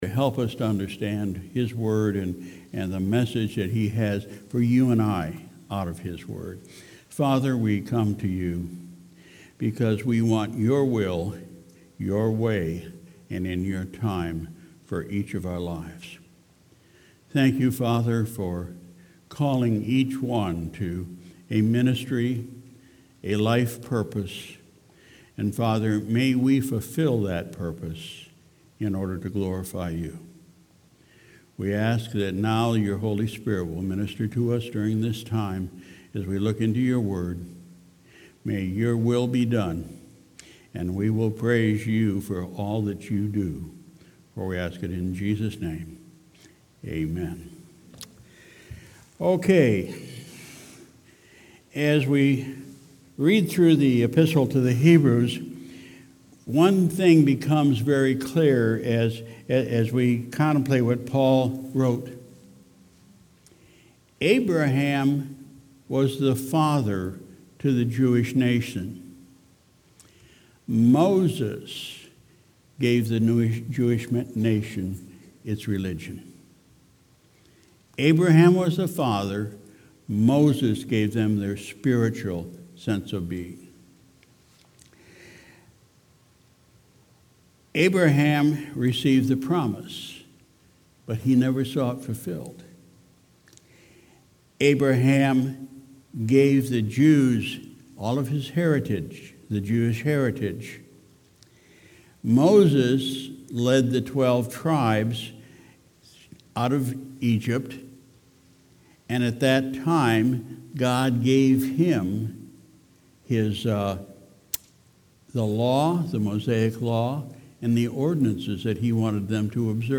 Sunday, July 14, 2019 – Evening Service